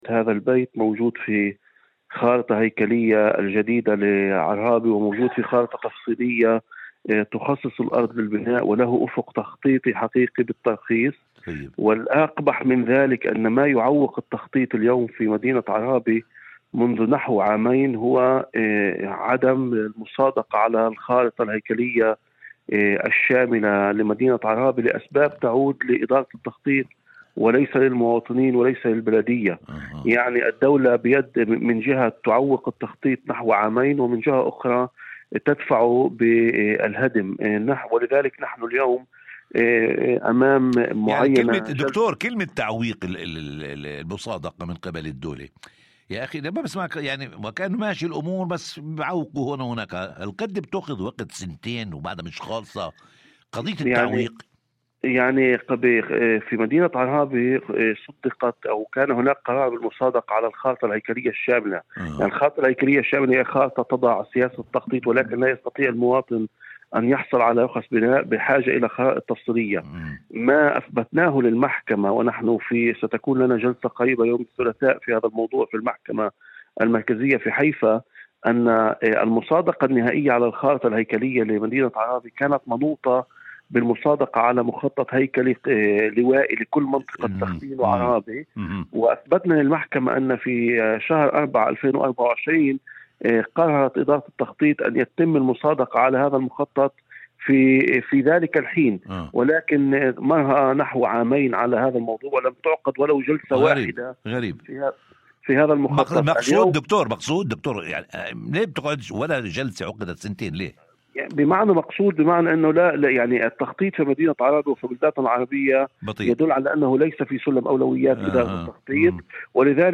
في مداخلة هاتفية